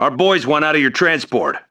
、 分类:维和步兵语音 您不可以覆盖此文件。
CHAT_AlliedSoldier56.wav